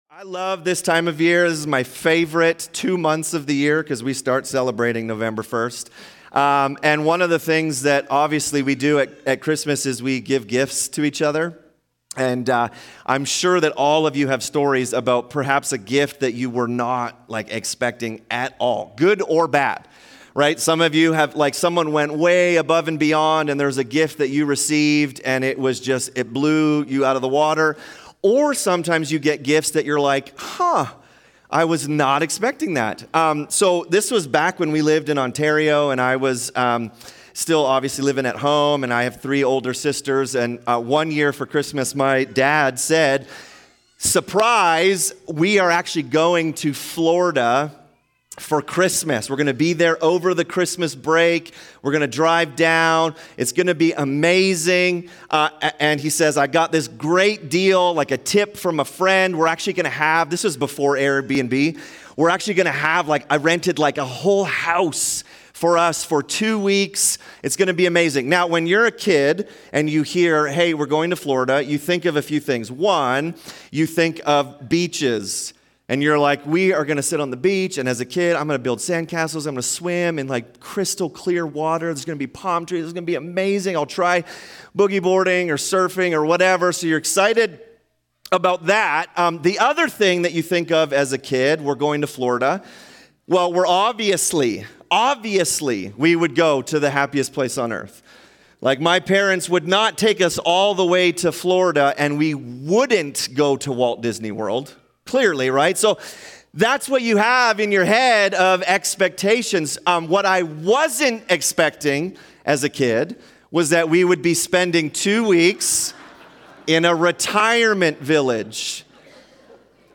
In this Christmas Eve message we look at John 1 which tells us that God became man. Jesus is fully man and fully God and therefore is the only one who could save humanity.
Subscribe via iTunes to our weekly Sermons